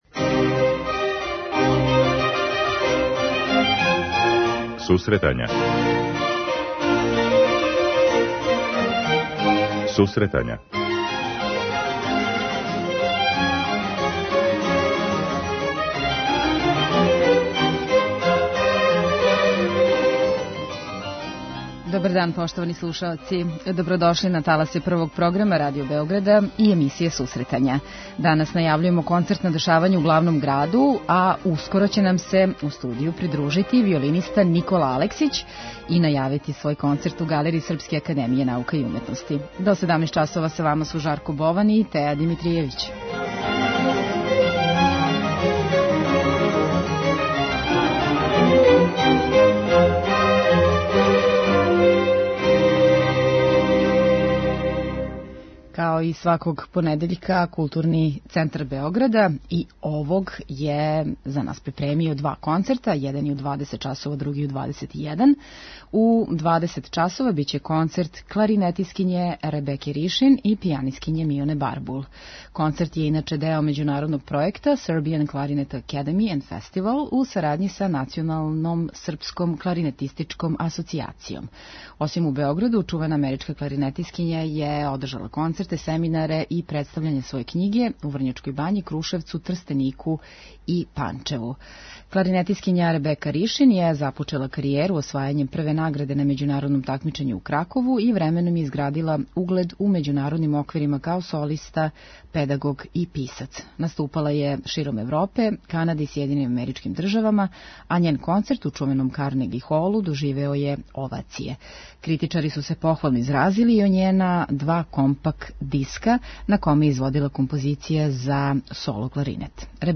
Гост емисије је виолиниста